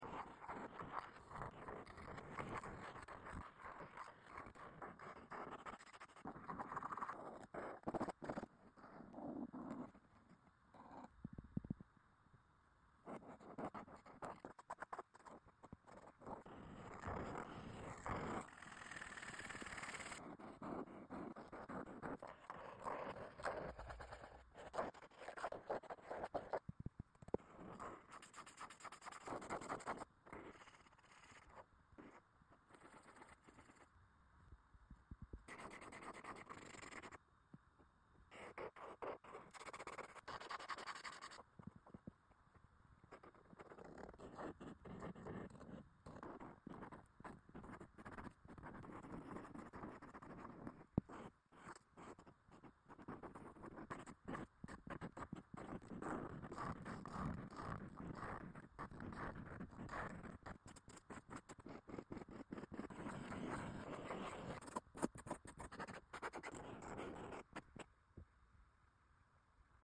ASMR September chalkboard calendar! 🧚‍♀🐸🌷🌙 sound effects free download